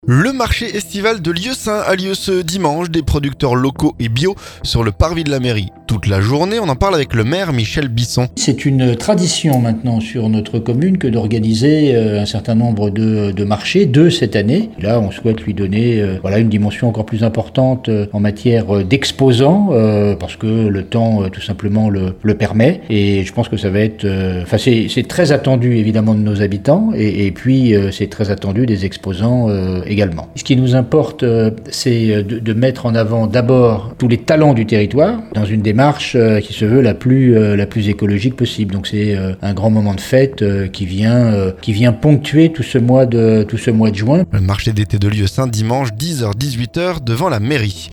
Des producteurs locaux et bios sur le parvis de la mairie de 10h à 18h. Rencontre avec le maire Miche Bisson.